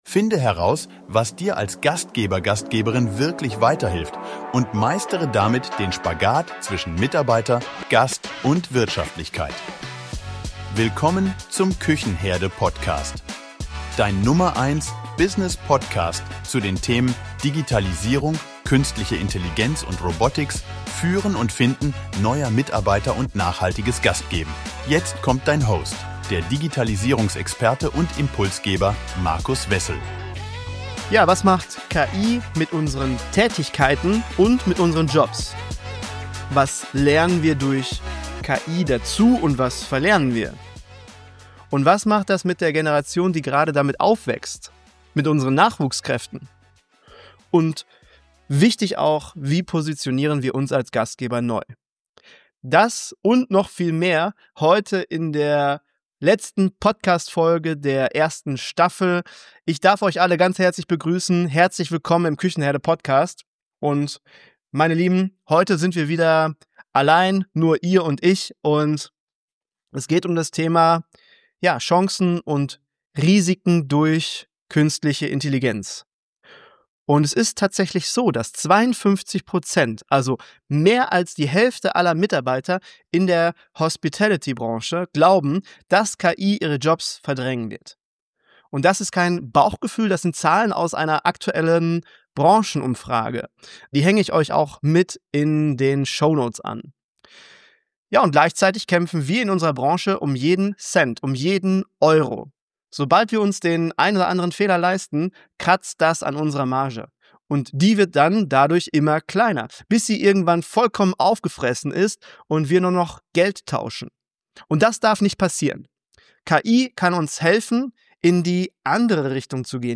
In dieser Solo-Folge spreche ich über die Risiken und Herausforderungen von Künstlicher Intelligenz in unserer Branche.